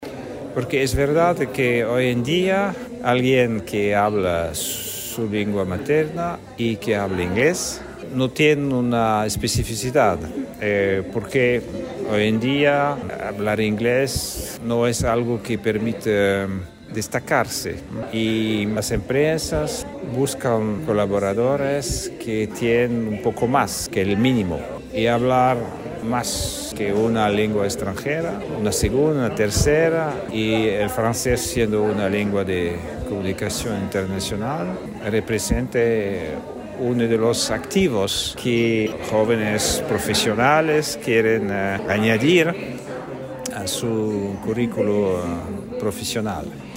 AUDIO : Embajador de Francia en Chile
embajador-de-francia.mp3